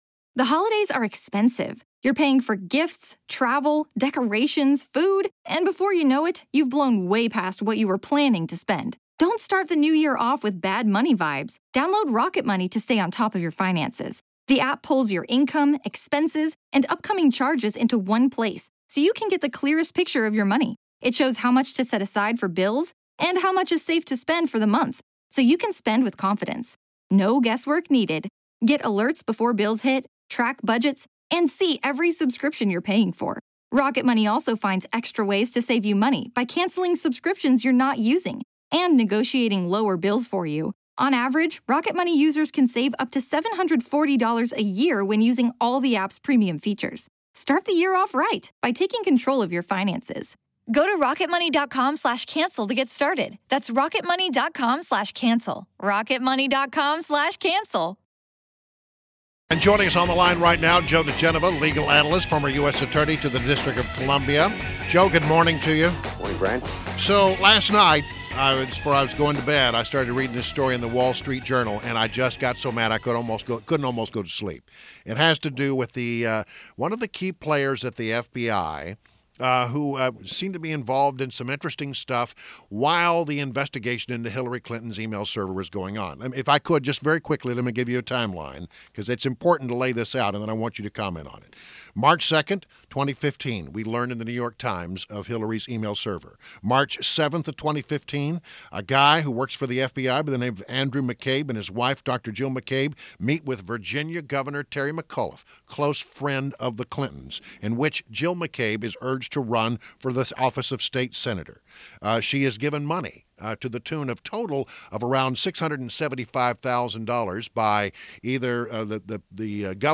INTERVIEW – JOE DIGENOVA — legal analyst and former U.S. Attorney to the District of Columbia